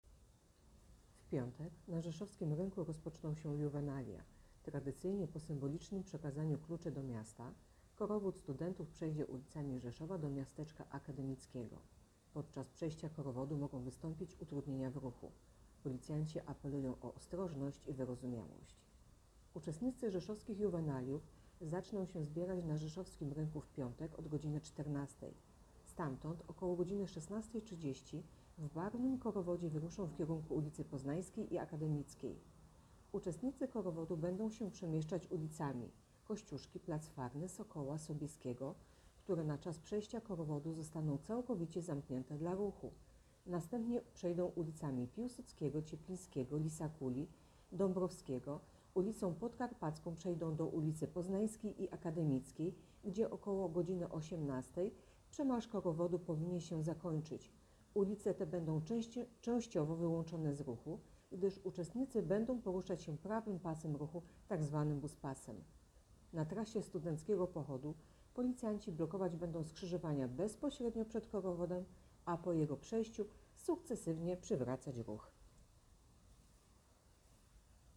Opis nagrania: Nagranie do tekstu Rzeszowskie Juwenalia - w piątek utrudnienia w ruchu.